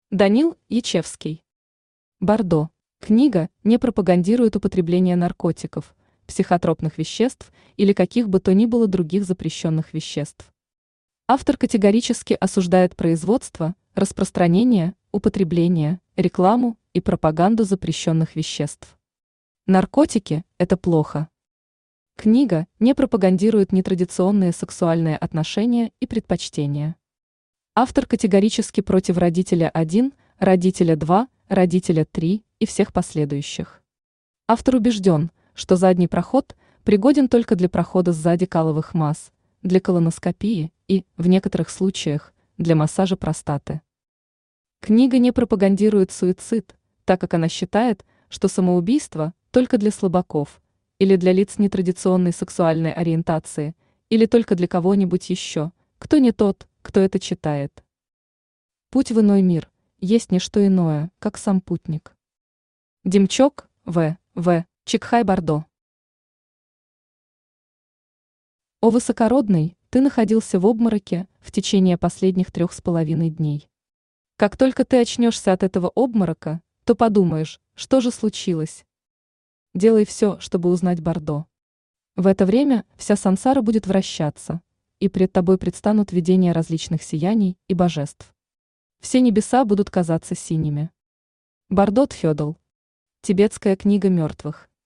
Аудиокнига Бардо | Библиотека аудиокниг
Aудиокнига Бардо Автор Данил Олегович Ечевский Читает аудиокнигу Авточтец ЛитРес.